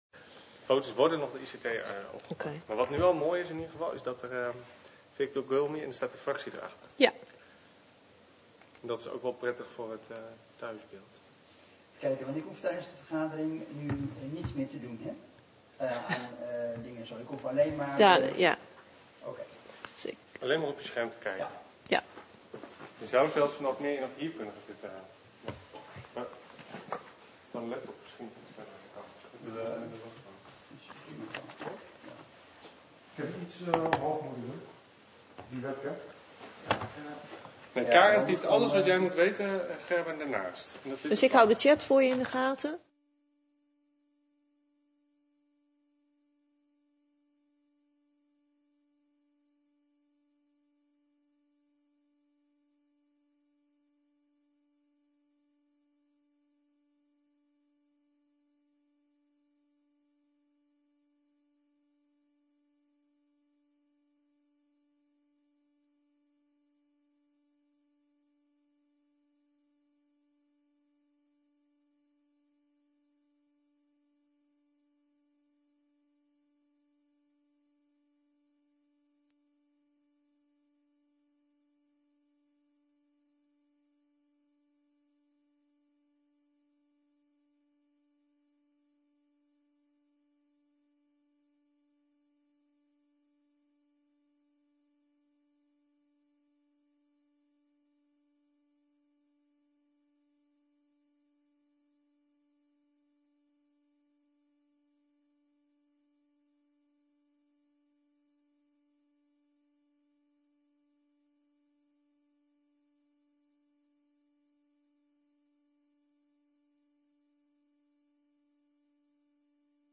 Locatie Digitale vergadering